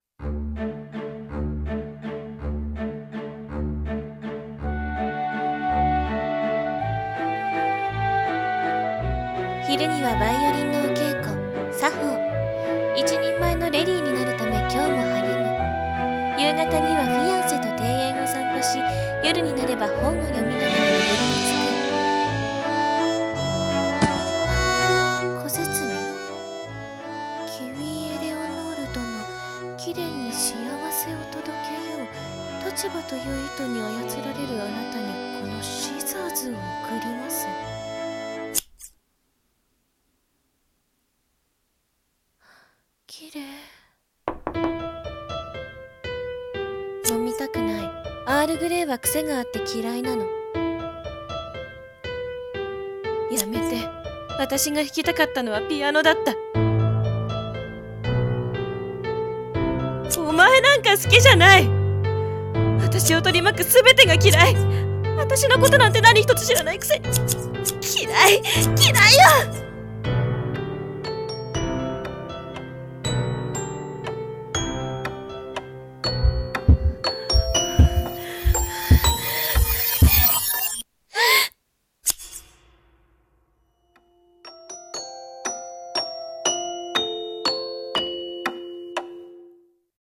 CM風声劇「鳥籠マリオネッタ」